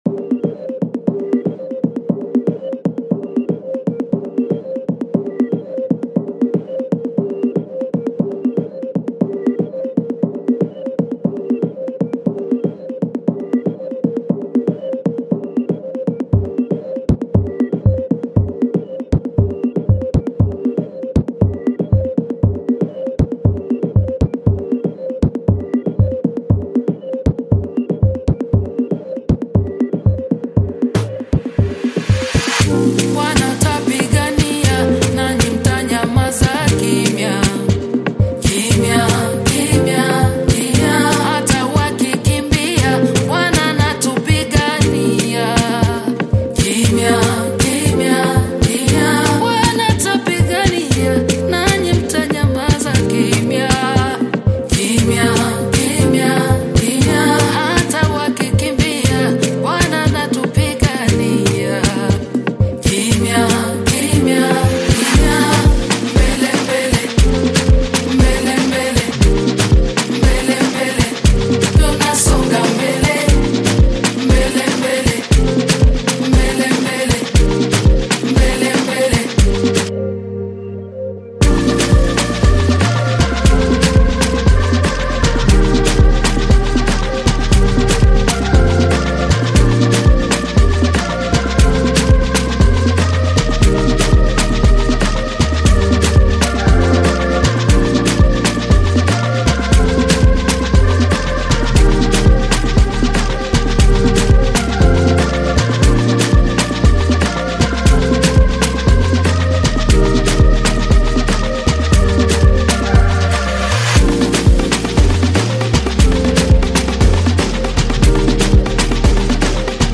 GOSPEL
Renowned Tanzanian gospel singer
spiritually uplifting and faith growing song